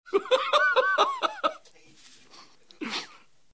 rofl3.wav